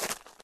added base steps sounds
ground_0.ogg